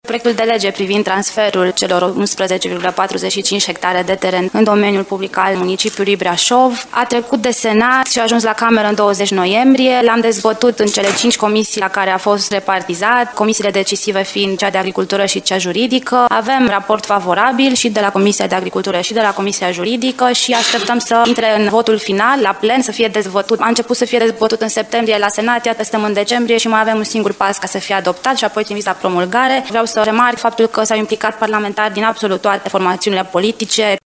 Proiectul Noului Spital al Braşovului a primit deja vot favorabil de la comisii, a arătat și deputatul de Brașov Roxana Mânzatu: